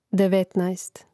devètnaest devetnaest